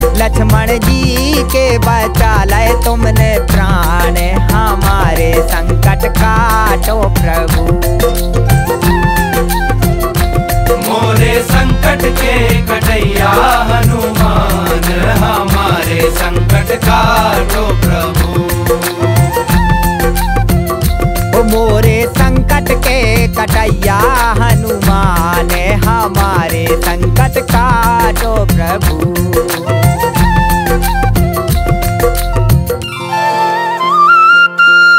Patriotic Hindi flag-wave melody hook caller tone with loud,